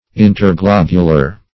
Search Result for " interglobular" : The Collaborative International Dictionary of English v.0.48: Interglobular \In`ter*glob"u*lar\, a. (Anat.)